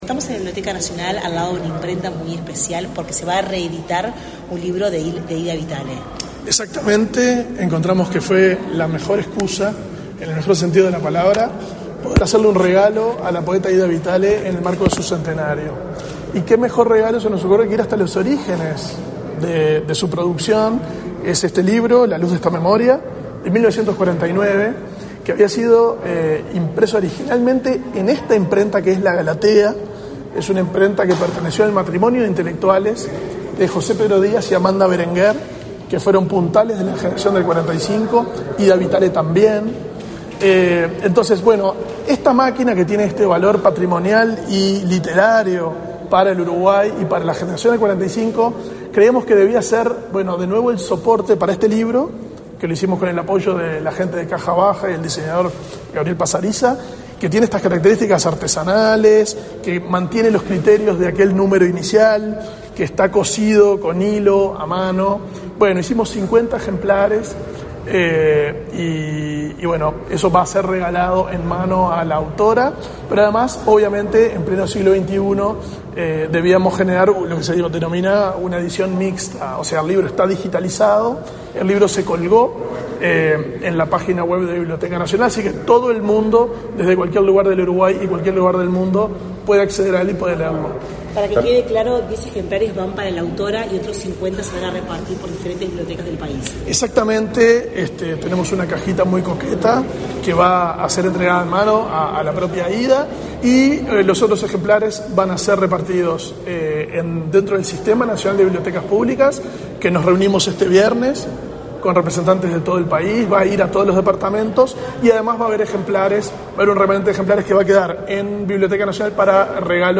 Declaraciones del director de la Biblioteca Nacional de Uruguay, Valentín Trujillo
Declaraciones del director de la Biblioteca Nacional de Uruguay, Valentín Trujillo 29/11/2023 Compartir Facebook X Copiar enlace WhatsApp LinkedIn La Biblioteca Nacional de Uruguay presentó, este 29 de noviembre, el libro "La luz de esta memoria", de la poeta uruguaya Ida Vitale, en homenaje al centenario de su nacimiento. En la oportunidad, el director de la institución cultural, Valentín Trujillo, realizó declaraciones.